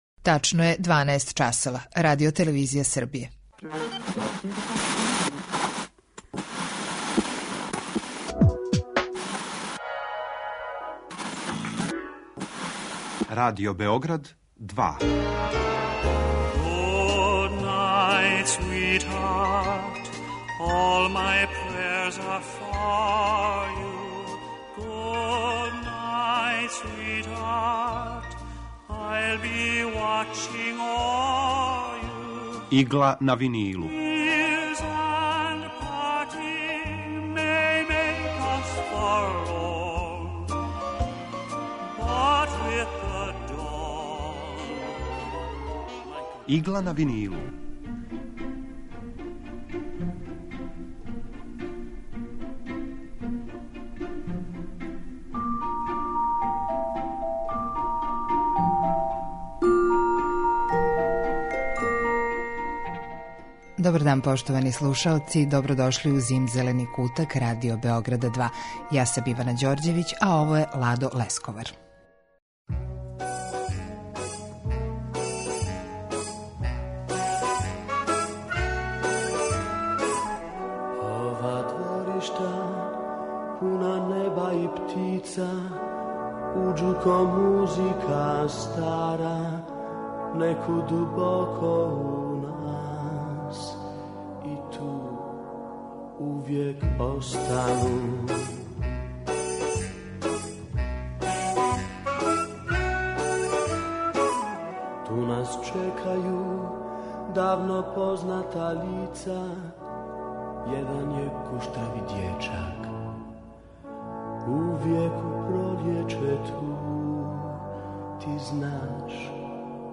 Евергрин